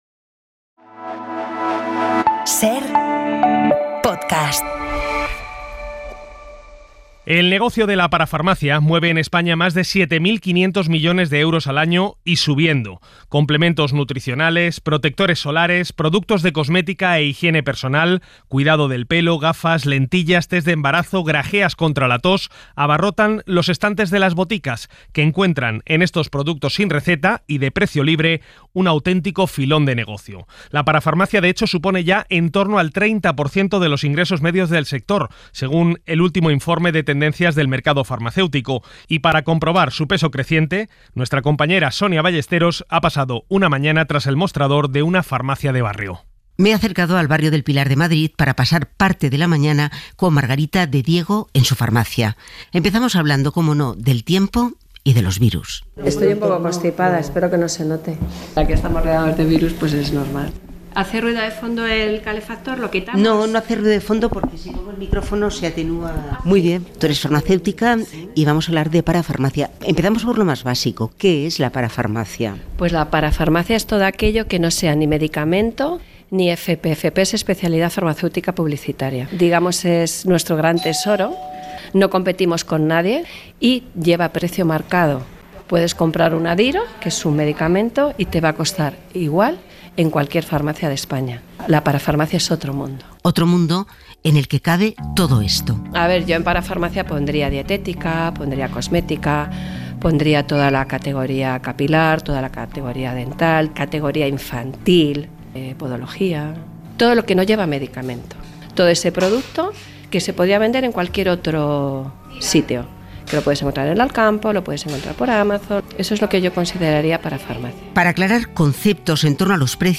Cremas faciales, protectores solares y productos de higiene copan casi el 40% de las ventas de las farmacias en España. Visitamos una botica de barrio en Madrid para conocer qué buscan los clientes y la importancia que sigue cobrando la parafarmacia en el sector.